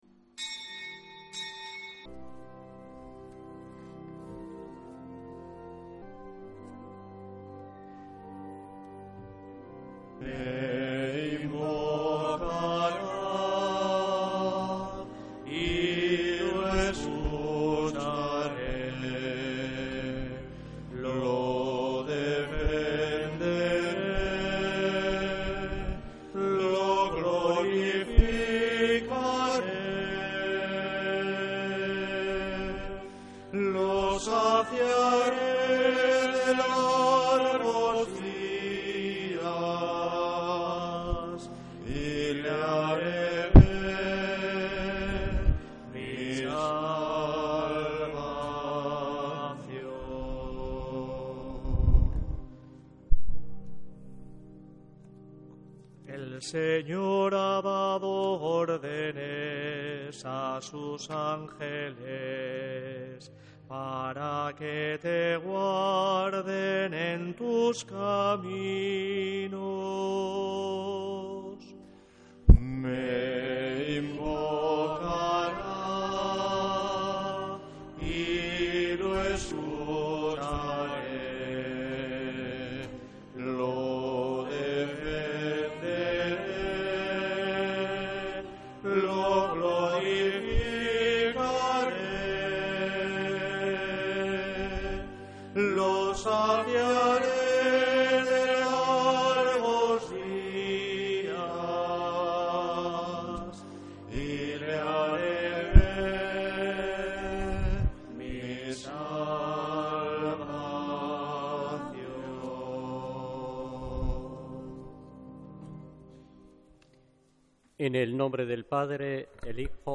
Santa Misa desde San Felicísimo en Deusto, domingo 1 de marzo de 2026